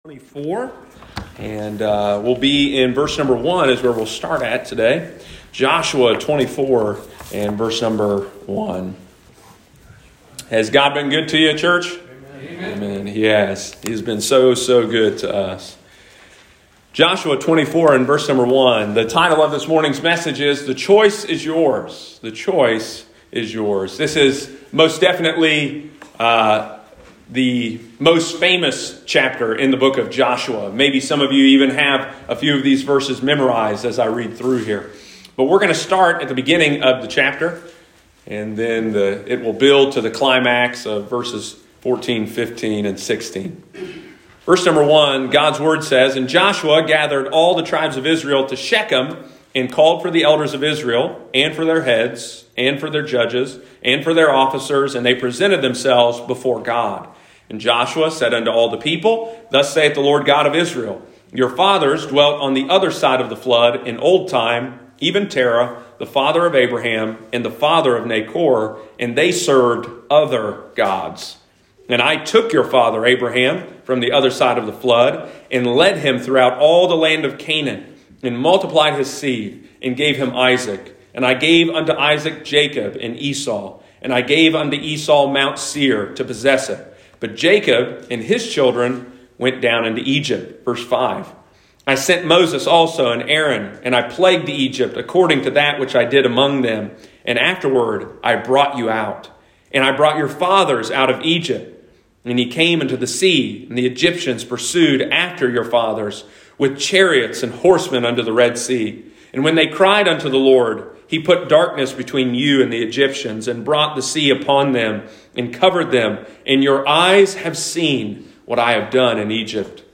Sunday morning, November 28, 2021.